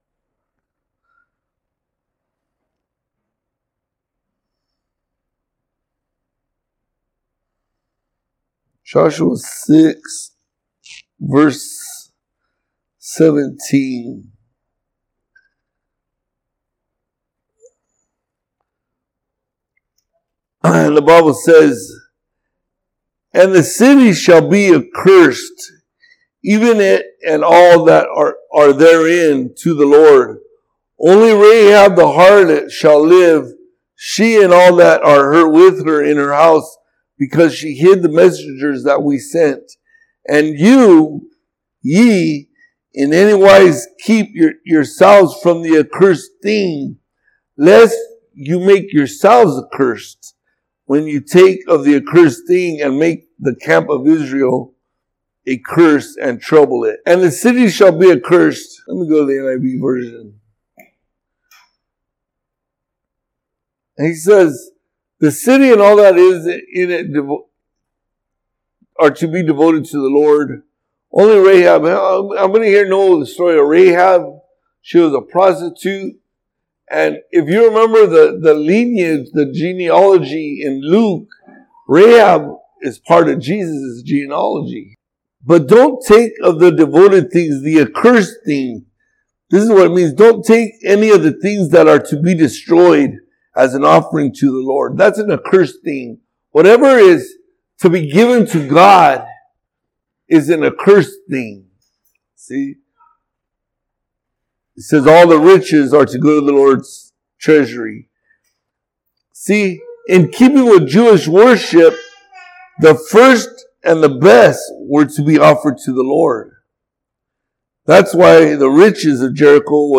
Podcast (piru-community-church-sermons): Play in new window | Download